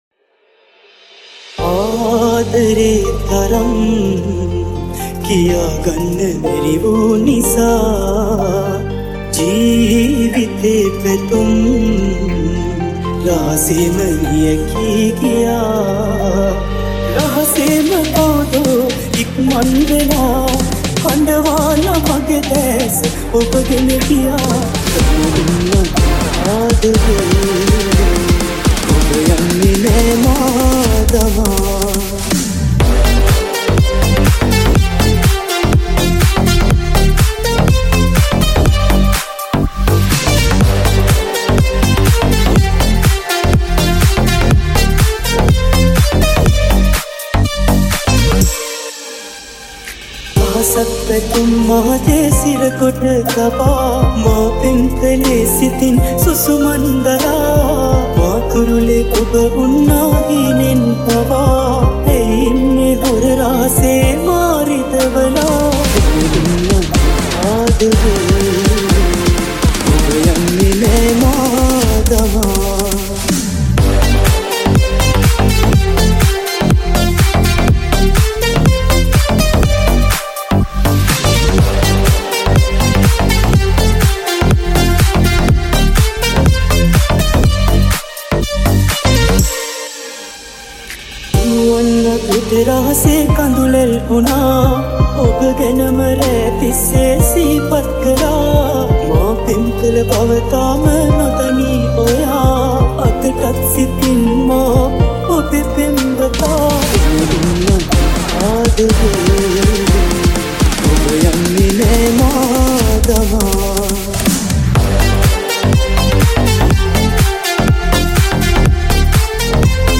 High quality Sri Lankan remix MP3 (2.1).